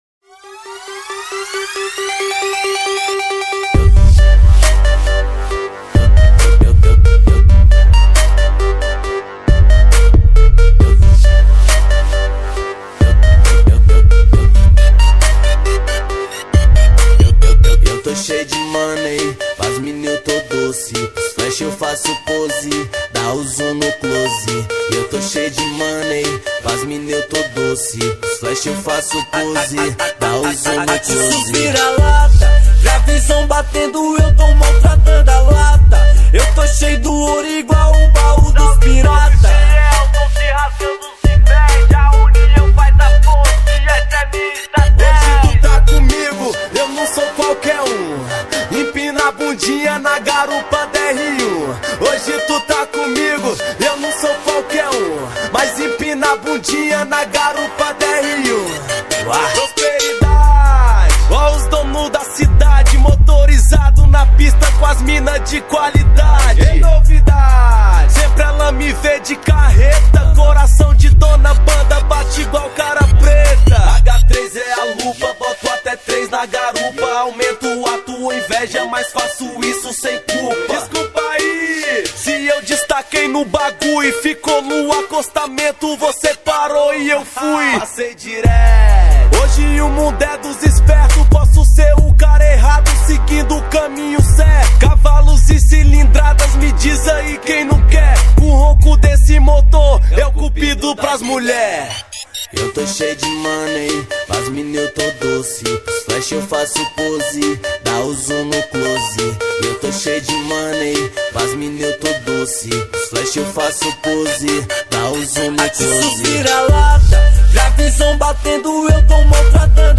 2025-02-04 14:46:09 Gênero: Rap Views